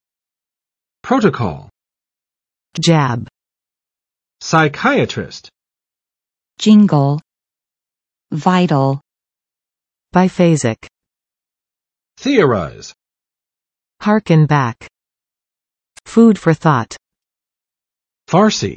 [ˋprotə͵kɑl] n. 议定书；协议；草案